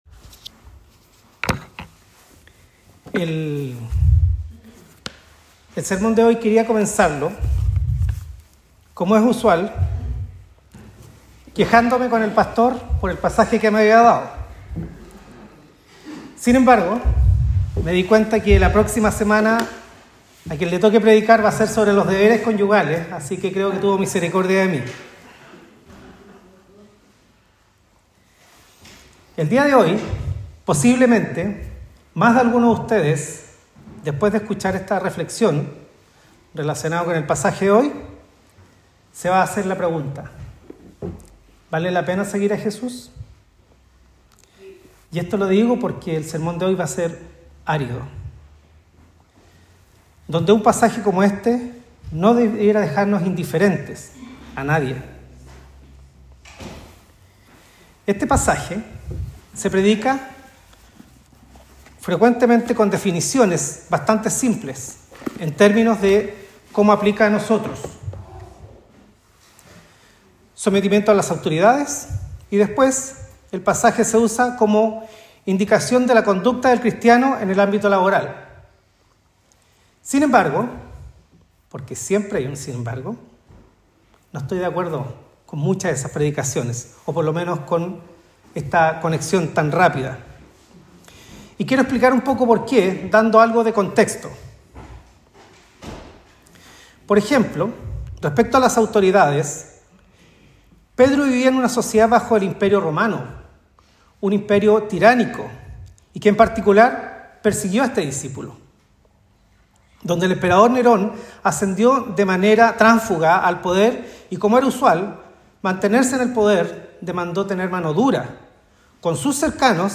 Sermón de 1 Pedro 2